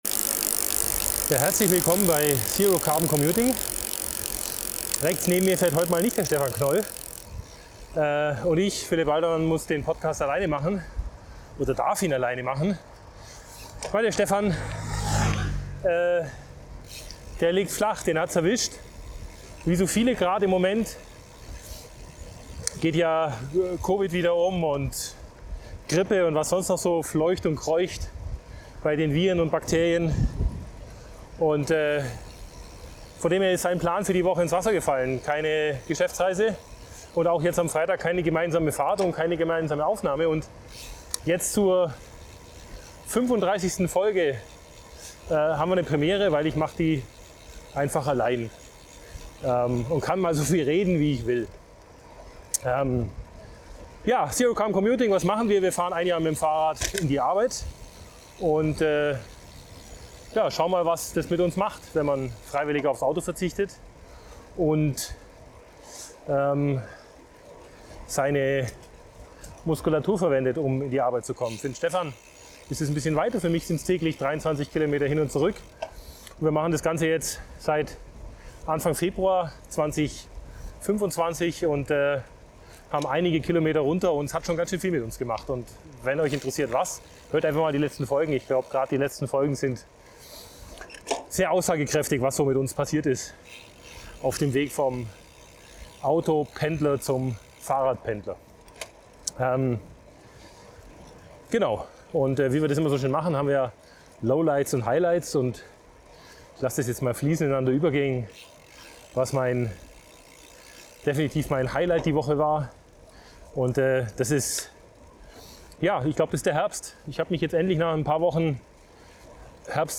Nichts neues - aber für den Podcast diese Woche bedeutet das nur eine Stimme